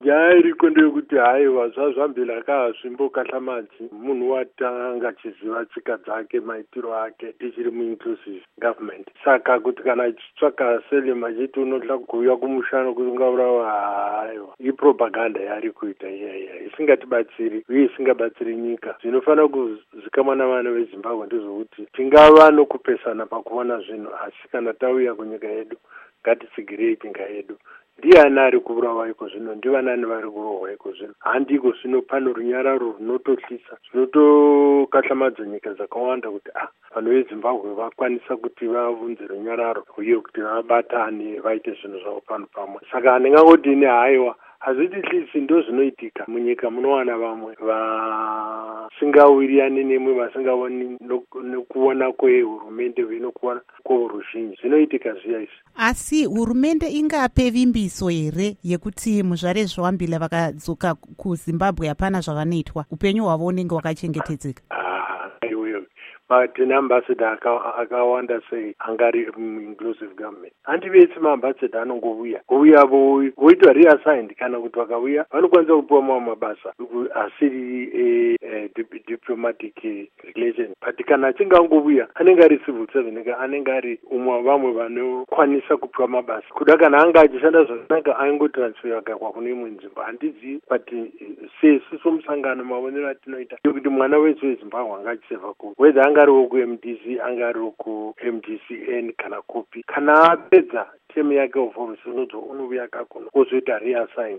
Hurukuro NaVa Rugare Gumbo